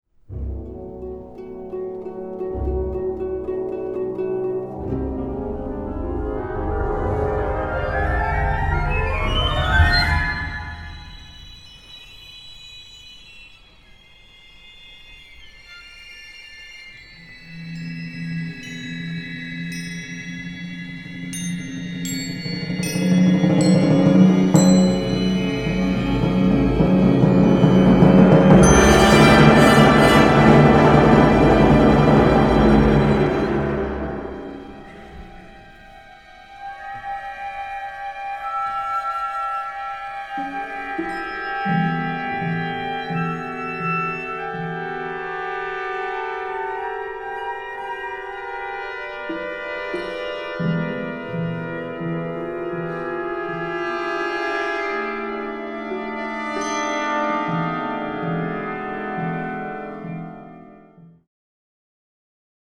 Audio excerpts from the world premiere